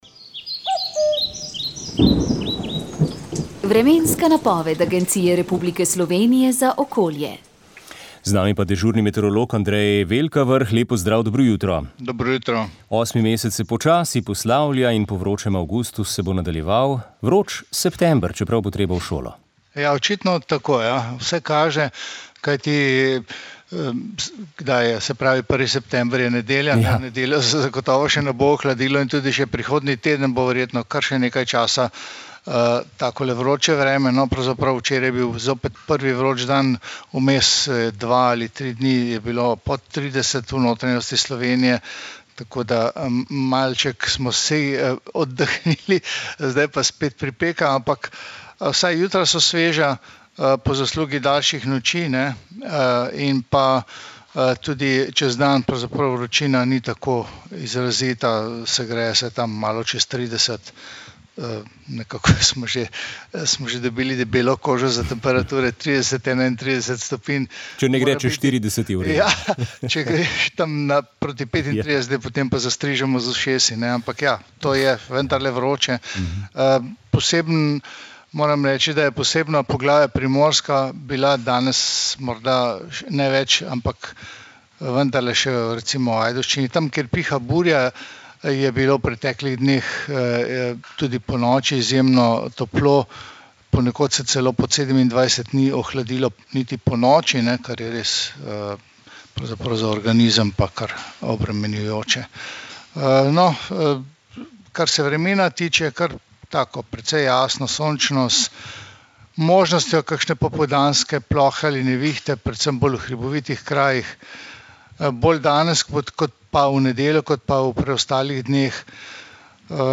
Vremenska napoved 29. avgust 2024